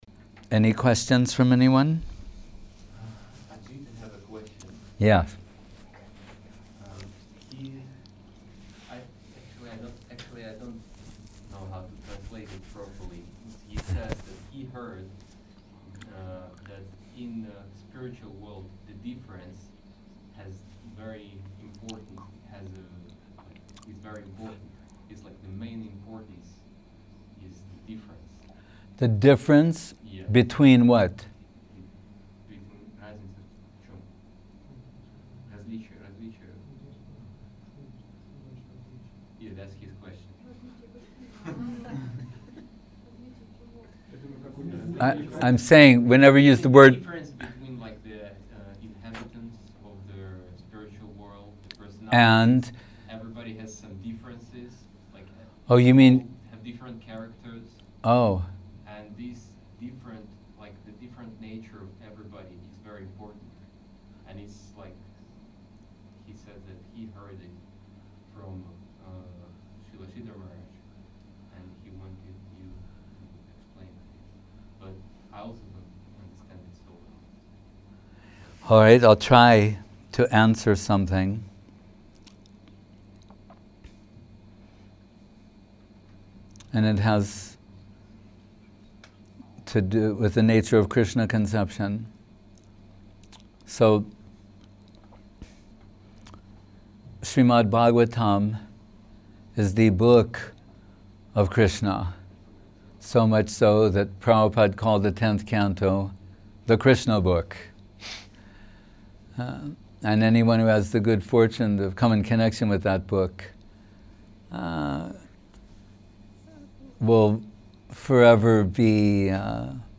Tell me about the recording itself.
Place: Gupta Govardhan Chiang Mai